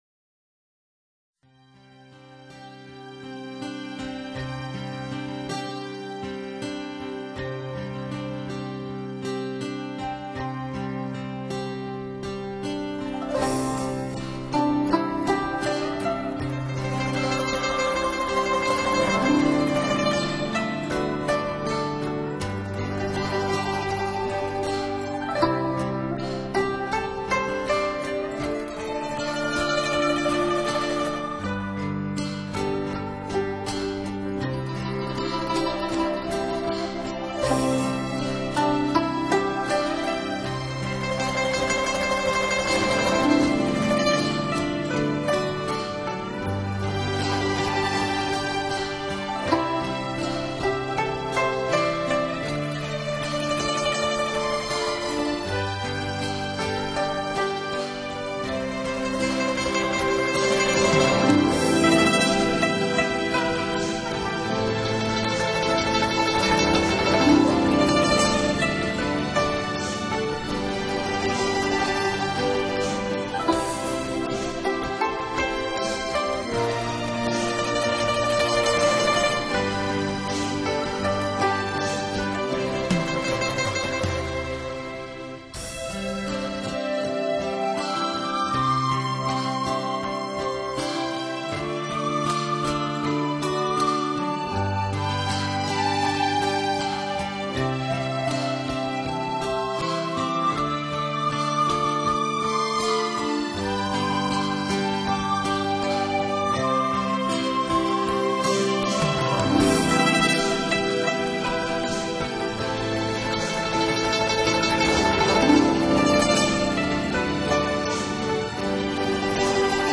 音乐类型：New  Age